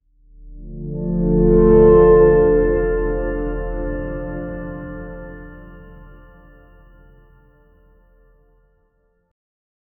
Soft, airy “ah… oh… ah” layers in two pitches, blended with light harpsichord plucks in D minor, long 8-second reverb, and a deep 220 Hz pad — timed in slow, steady pulses for a calm-before-the-storm cinematic SFX in the style of Interstellar’s pacing. 0:10
soft-airy-ah-oh-ah-bry54pwd.wav